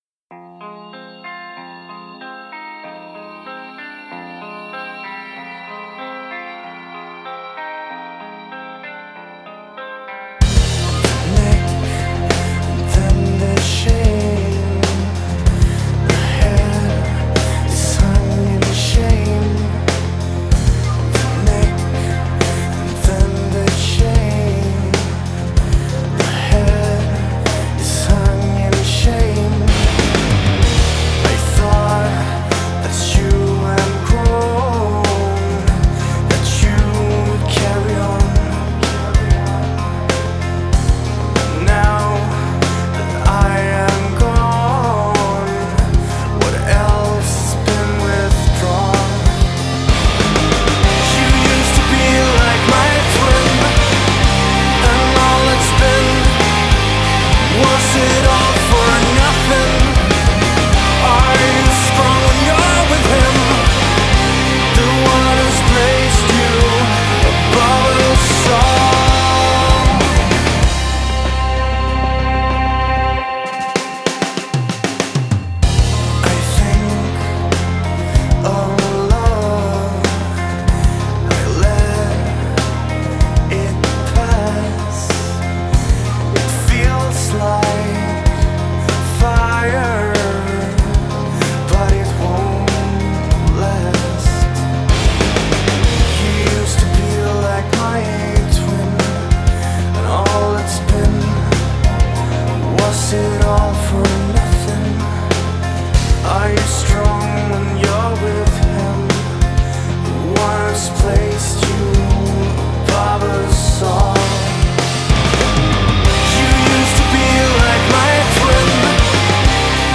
Depressive Rock